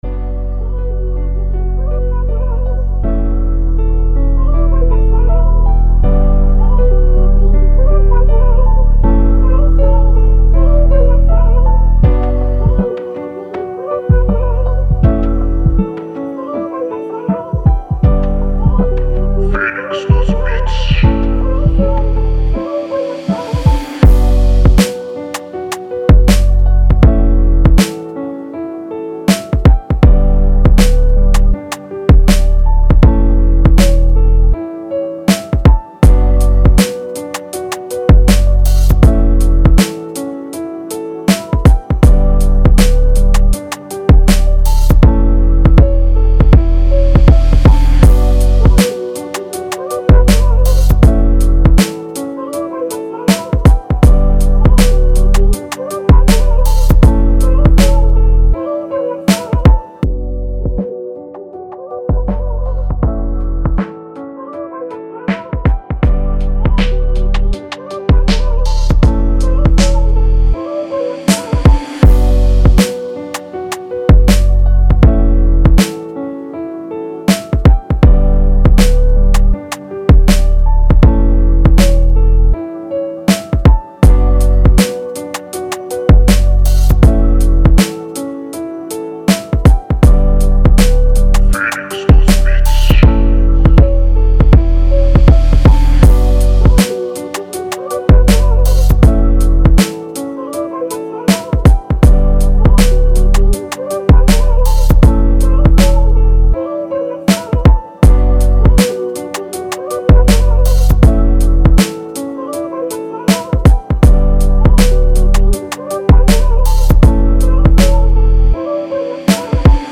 Emotional Sad RNB Instrumental